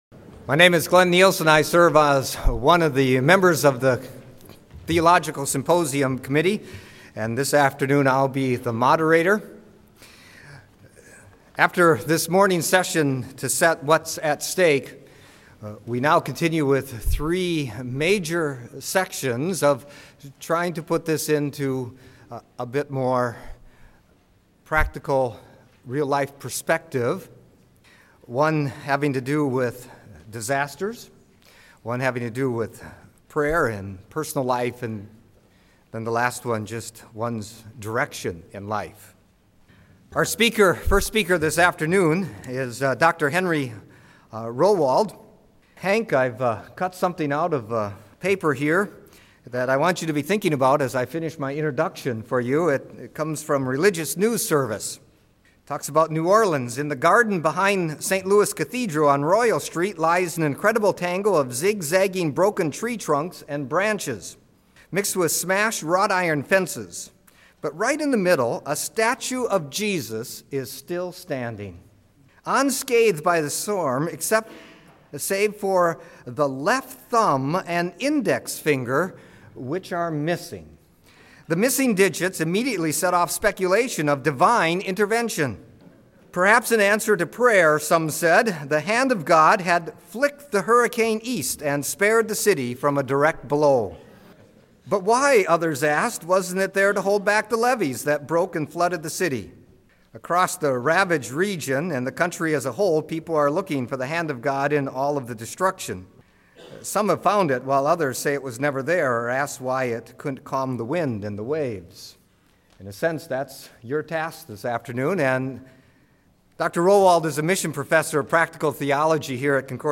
Bible Study; Lecture